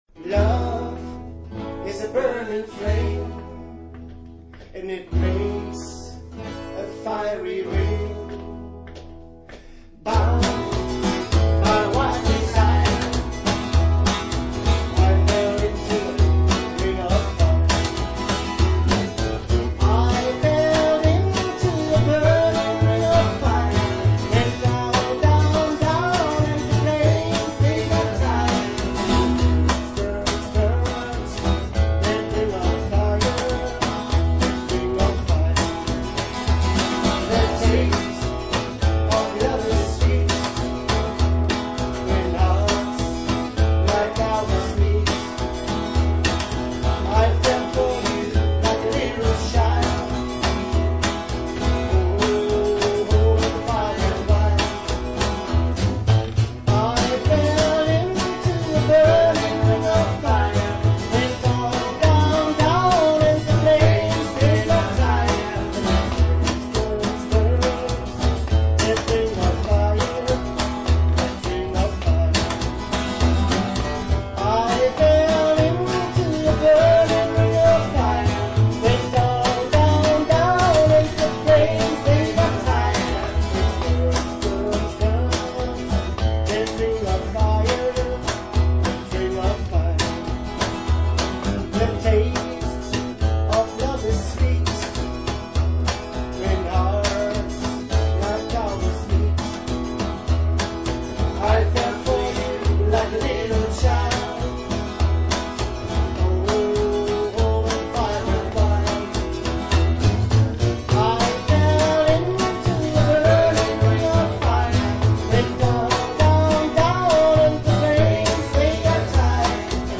Good Rockin Acoustic Music